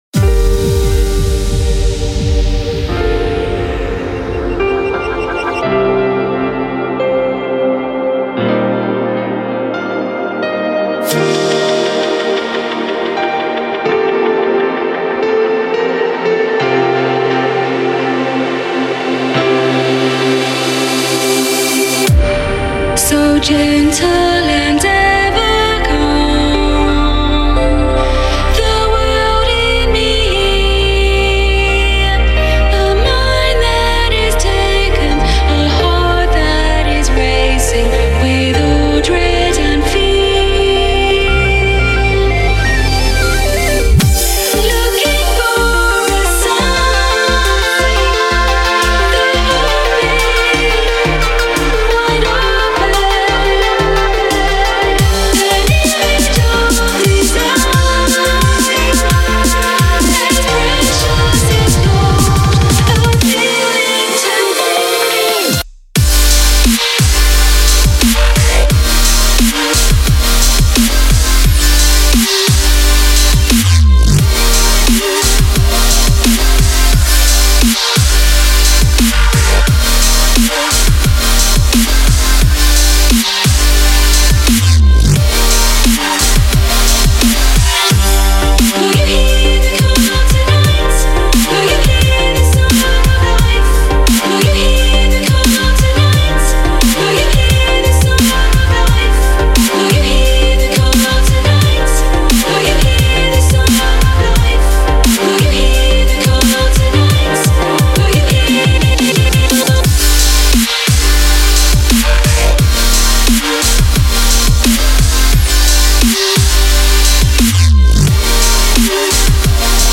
это завораживающий трек в жанре транс